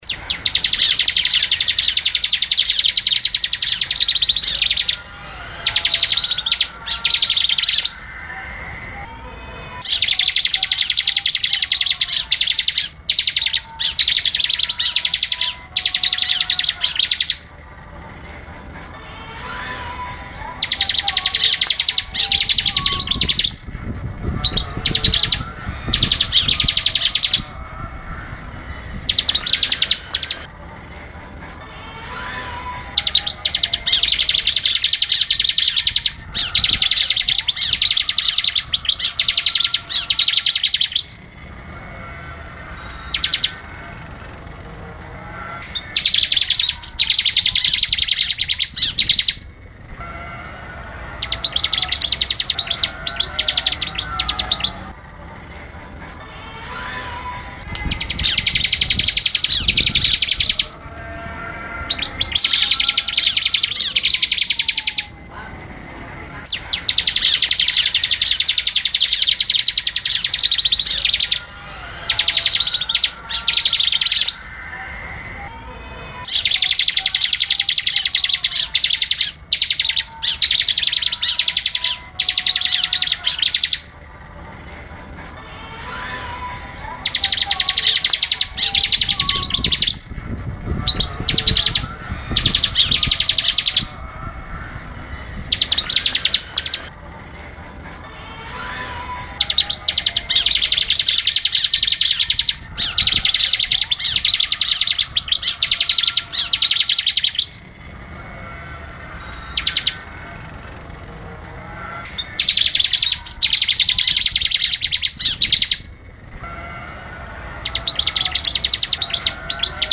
Download suara ciblek kristal super gacor ngebren mp3 dari berbagai variasi suara kicau burung ciblek untuk masteran atau isian
Suara Ciblek Kristal Ngebren Mp3